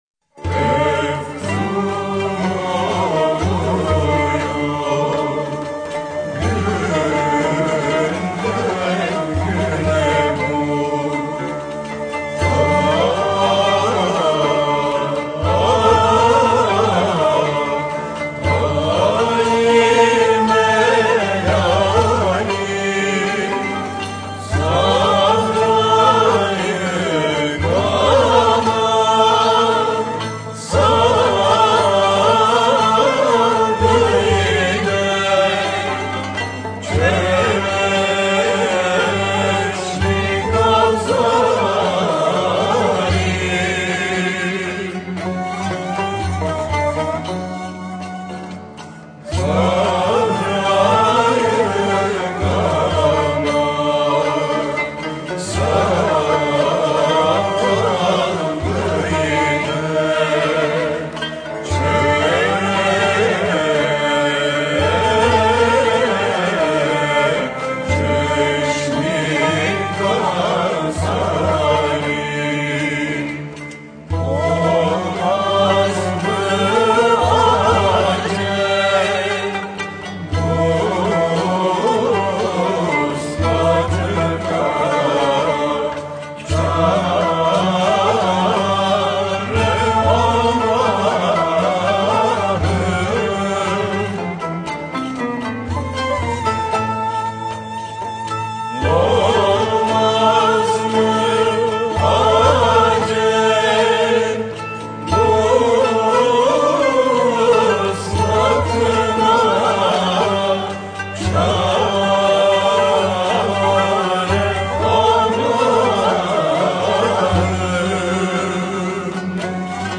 Eser: Efzun oluyor günden güne bu hal-i melalim Bestekâr: Arif Bey (Hacı) Güfte Sâhibi: Belirsiz Makam: Hicaz Form: Şarkı Usûl: Sengin Semai Güfte: -...